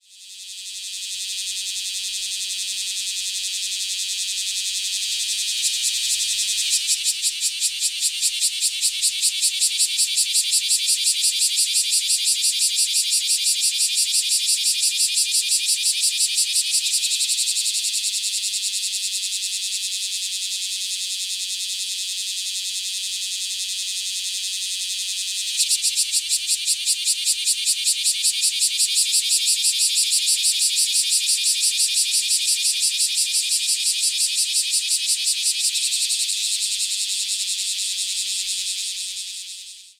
クマゼミの鳴き声
シャワシャワシャワシャワ、夏になると市街地の公園なのでも沢山鳴いています。
＊ 沖縄の動物・植物達のコーナー 録音：SonyリニアPCMレコーダーPCM-M10 国頭村にて録音
kumazemi-call.mp3